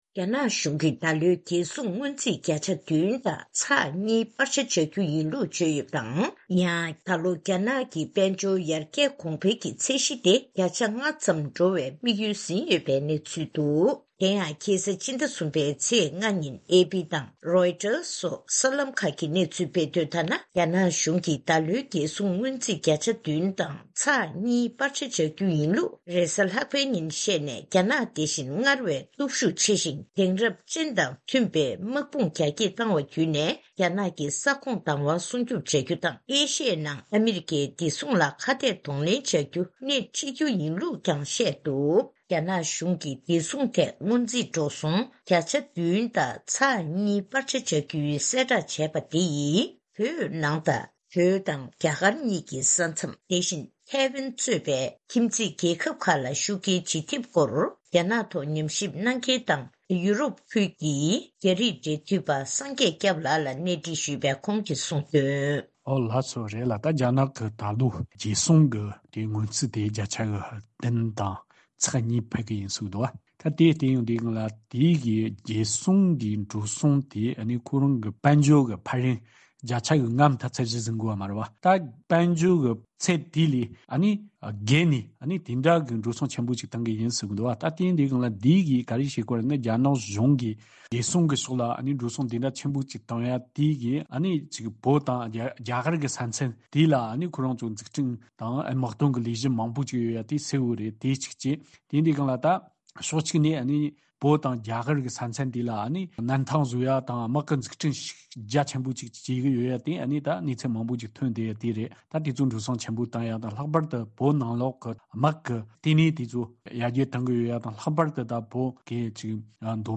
གནས་འདྲི་ཞུས་ནས་གནས་ཚུལ་ཕྱོགས་བསྒྲིགས་ཞུས་པ་ཞིག་གསན་རོགས་གནང་།